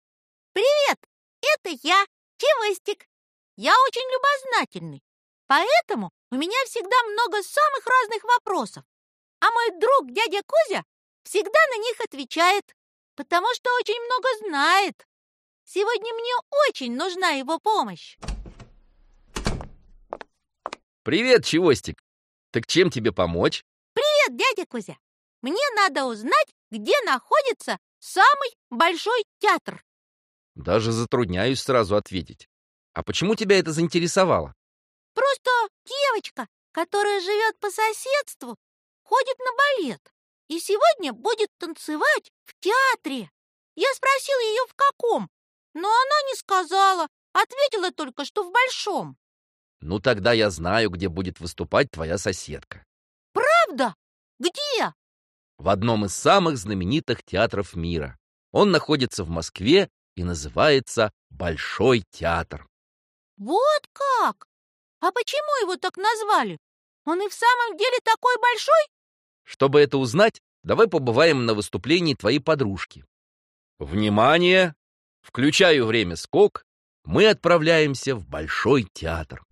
Аудиокнига Большой | Библиотека аудиокниг
Прослушать и бесплатно скачать фрагмент аудиокниги